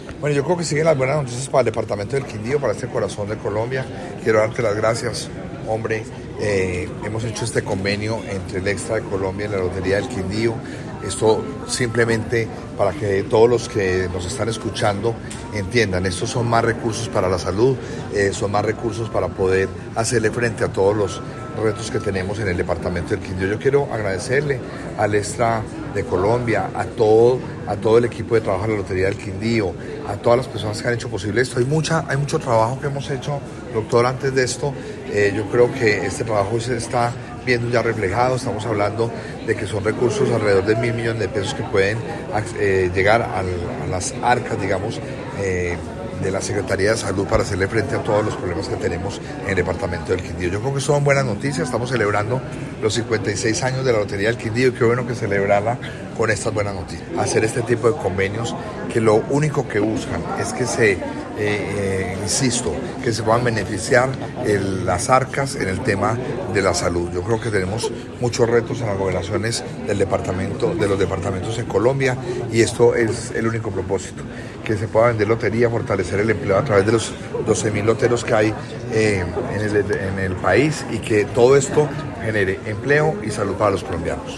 Audio de Roberto Jairo Jaramillo Cárdenas, Gobernador del Quindío: